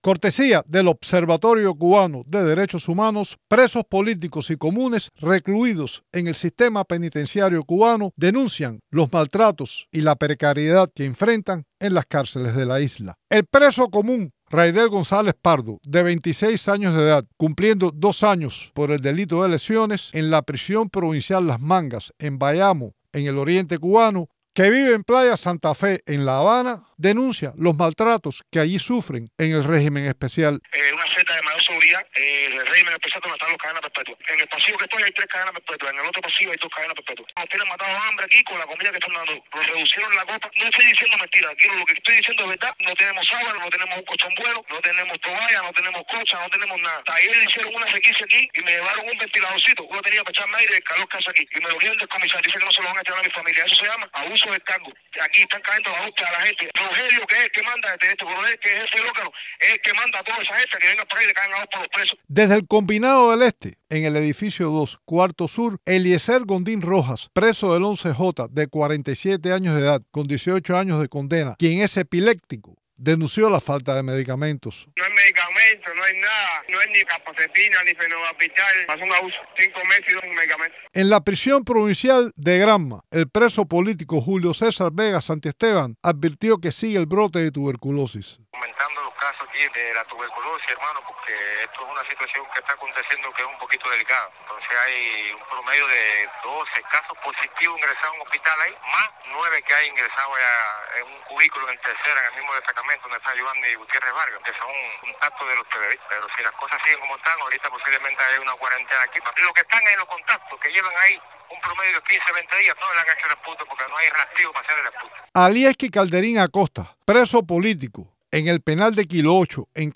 Testimonios de presos en Cuba sobre las críticas condiciones de las cárceles
Presos políticos y comunes recluidos en el sistema penitenciario cubano denuncian los maltratos y la precariedad que enfrentan en las cárceles de la isla.